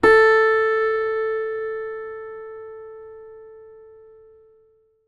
ZITHER A 2.wav